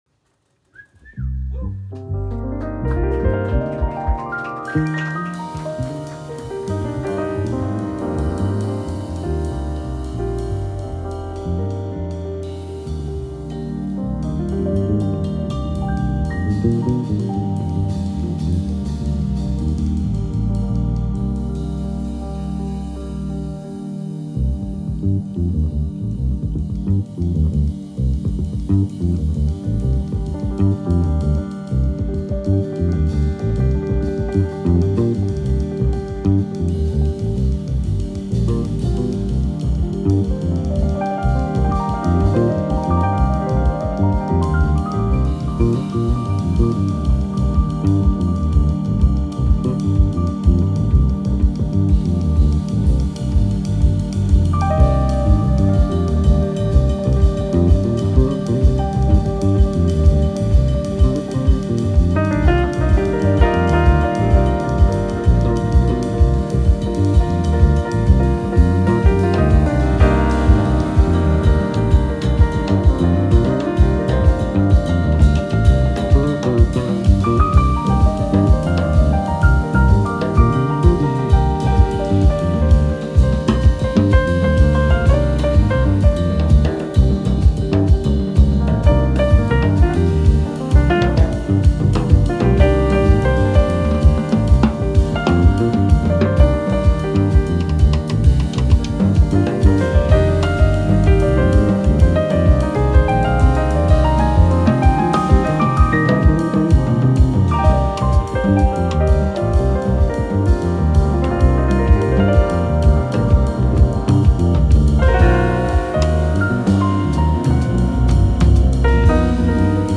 Трек размещён в разделе Зарубежная музыка / Джаз.